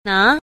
5. 拿 – ná – nã (cầm, nắm)